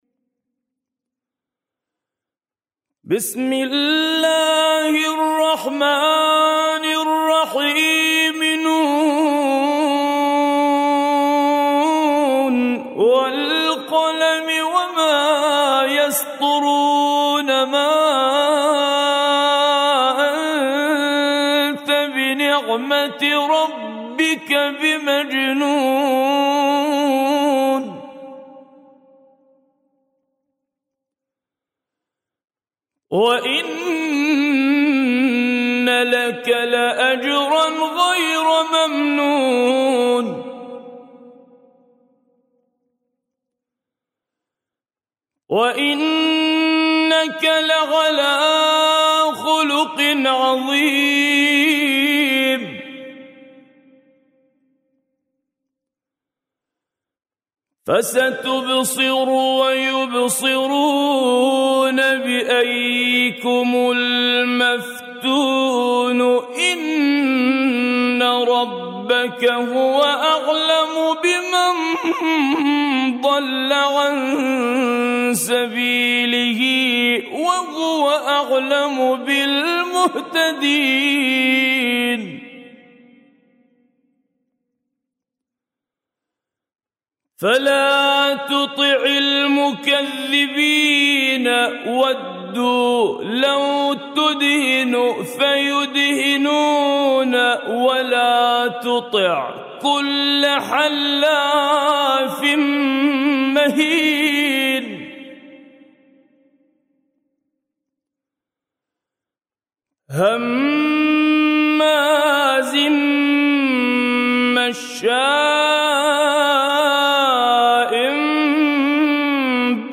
سورة القلم - بالطور العراقي - لحفظ الملف في مجلد خاص اضغط بالزر الأيمن هنا ثم اختر (حفظ الهدف باسم - Save Target As) واختر المكان المناسب